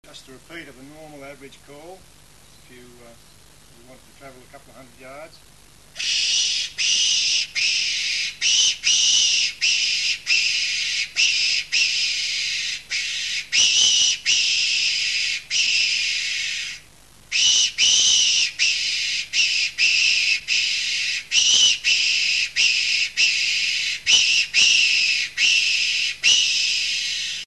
The Tenterfield Fox Whistle, when blown, emits a squeal very similar to the sound made by a rabbit in distress; but with a little practice it can produce a variety of distress calls which adds immensely to its versatility.
Listen to the range of sounds the whistle can make by pressing the buttons below.